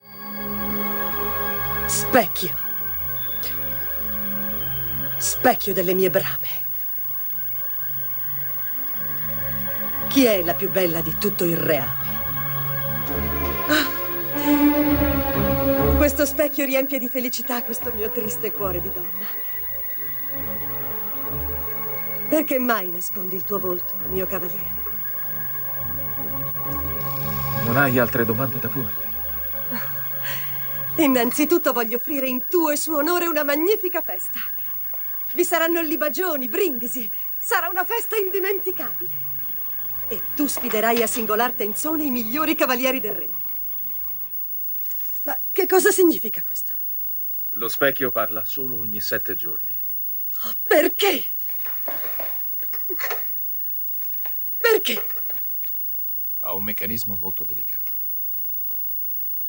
nel film TV "C'era una volta Biancaneve", in cui doppia Gudrun Landgrebe.